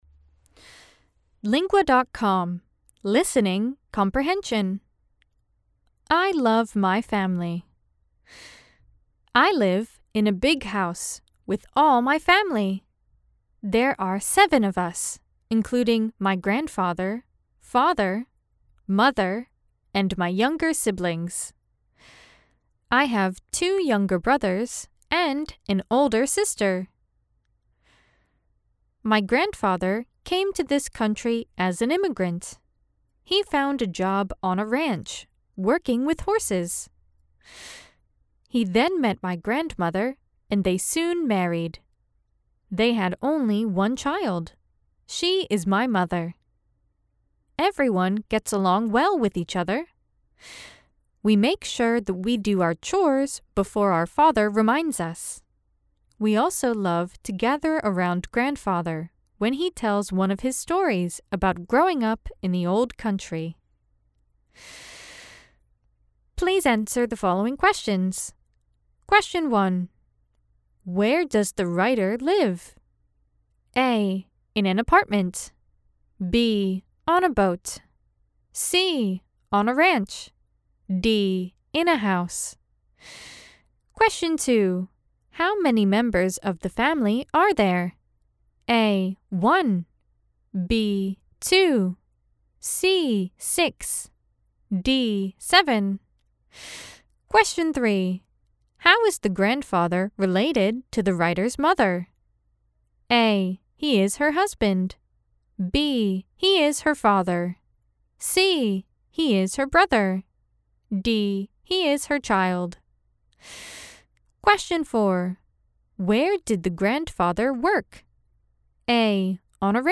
Estados Unidos